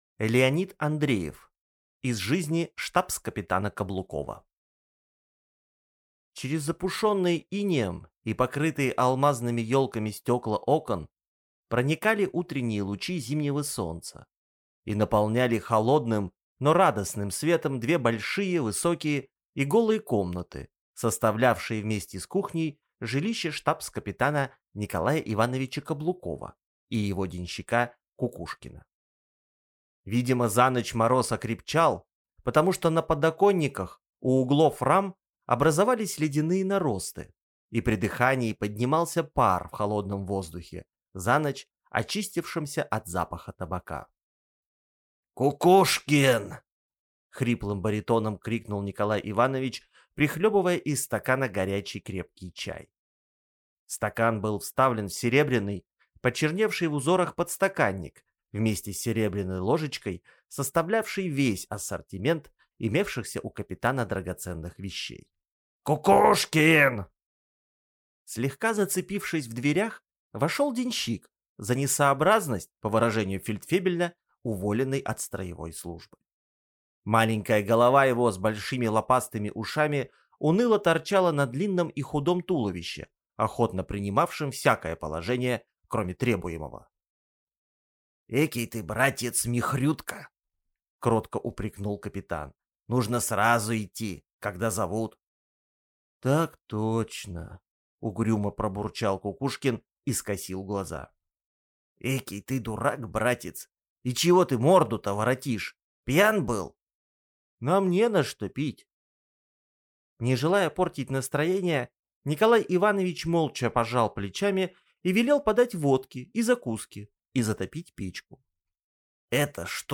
Аудиокнига Из жизни штабс-капитана Каблукова | Библиотека аудиокниг
Прослушать и бесплатно скачать фрагмент аудиокниги